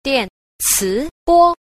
7. 電磁波 – diàncíbō – điện từ ba (sóng điện từ)